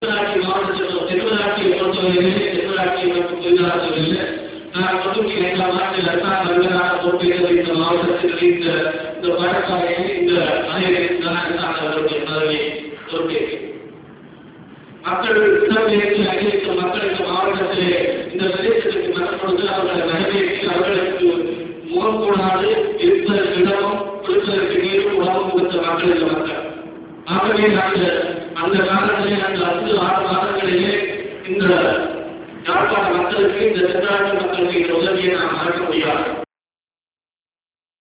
TamilNet releases relevant parts of GA's speech in audio
TamilNet publishes the relevant parts of the speech by Government Agent of Jaffna at a meeting in Chavakachcheri on 27 December 2003 where he asserted that the Sri Lankan government “did not spend a red cent” on rehabilitation in the northern peninsula in the last two years.